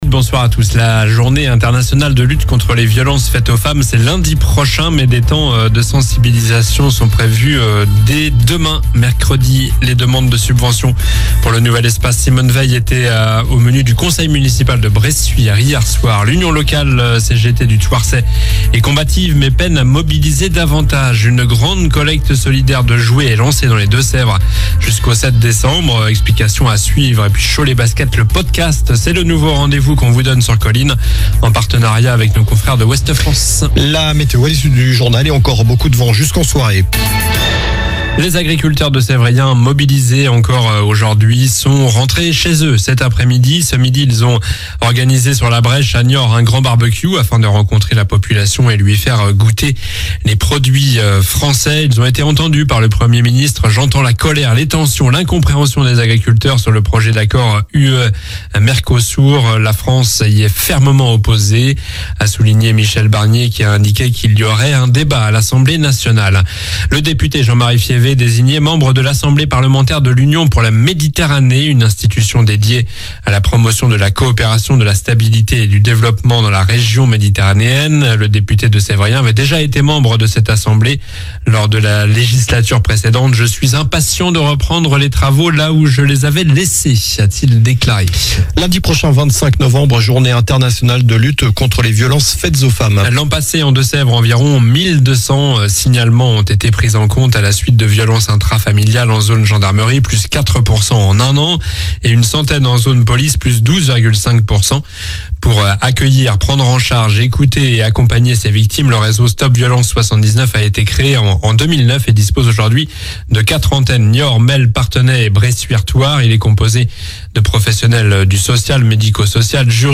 Journal du mardi 19 novembre (soir)